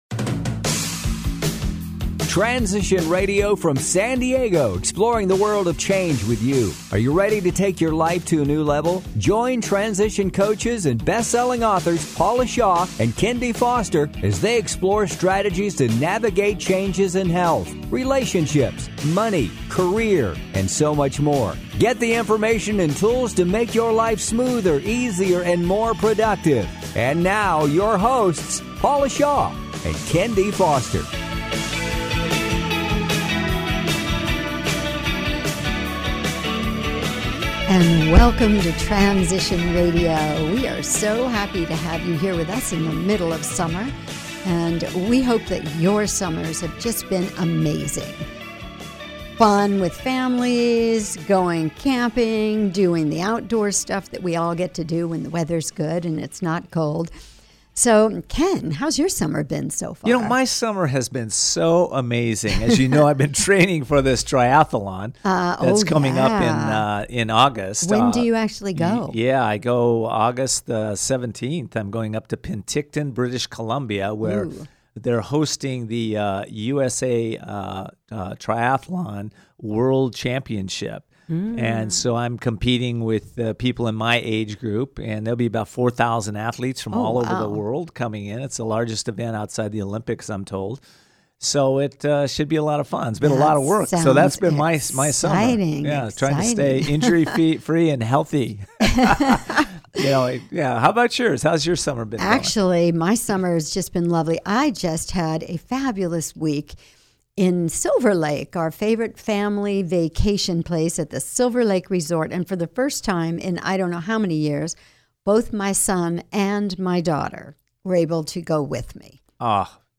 Transition Radio Show